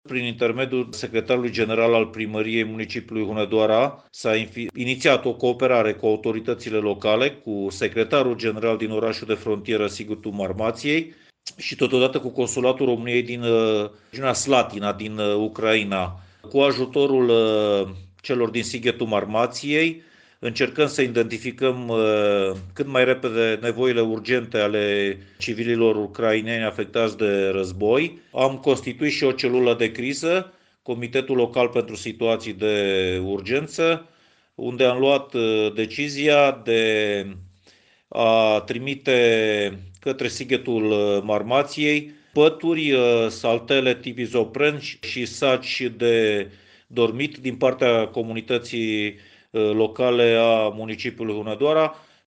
Printre altele, se pregătește un transport cu pături, saci de dormit și saltele, care să ajungă cât mai repede la Sighetu Maramației, spune primarul Dan Bobouțanu.